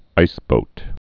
(īsbōt)